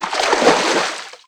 MISC Water_ Splash 07.wav